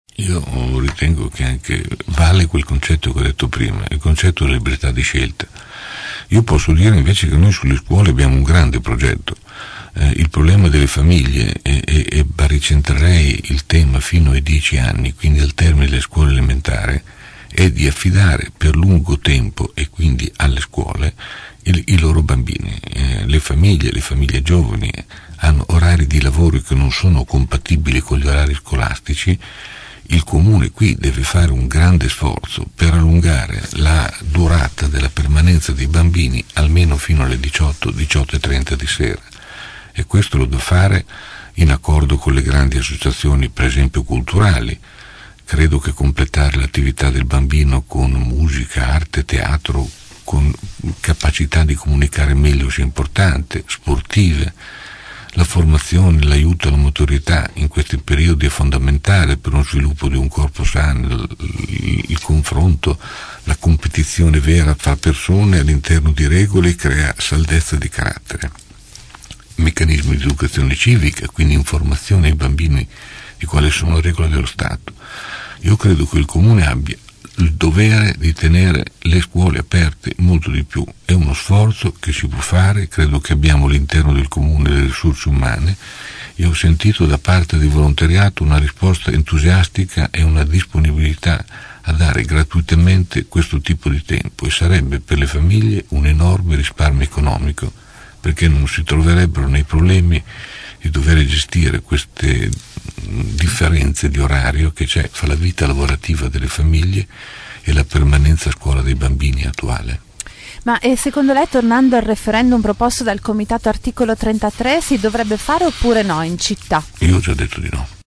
ospite questa mattina dei nostri studi.